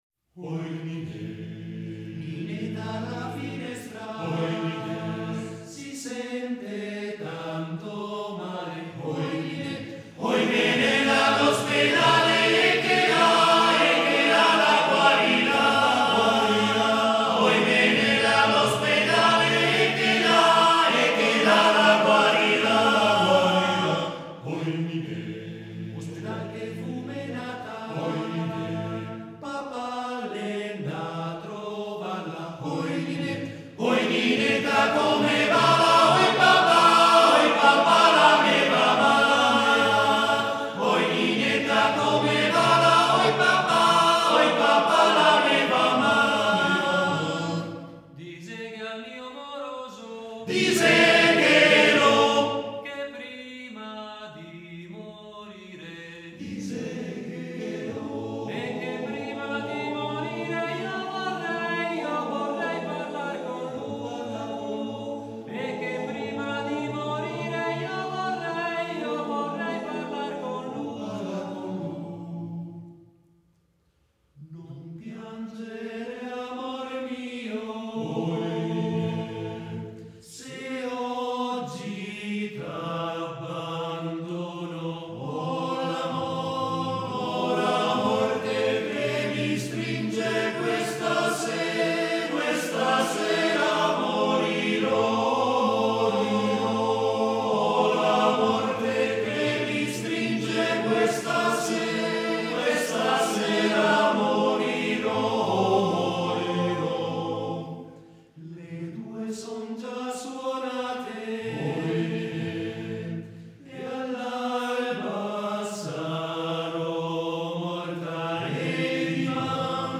4. Nineta (canto popolare recuperato dalla fam.